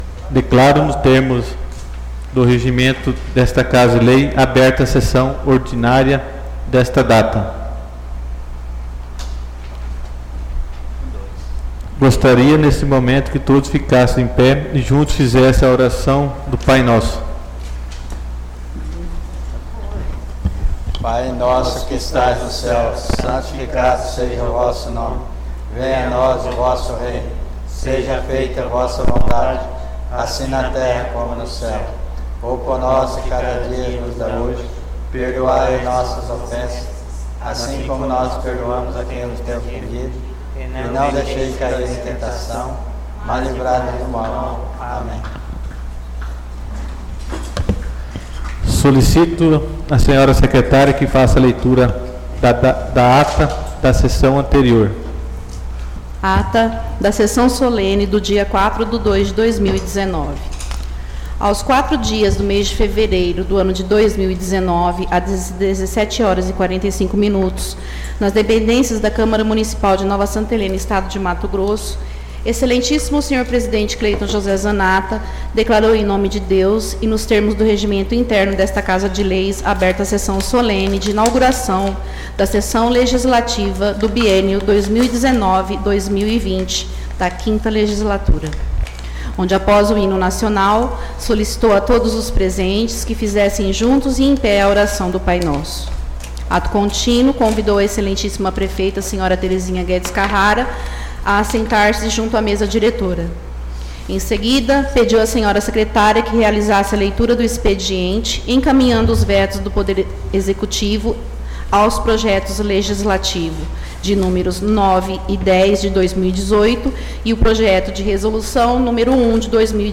Audio Sessão Ordinária 11/02/2019